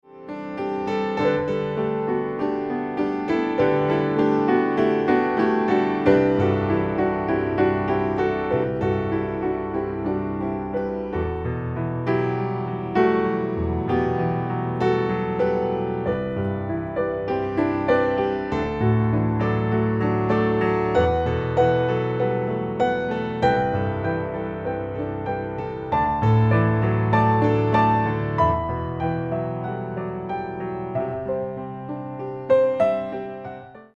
a series of instrumental recordings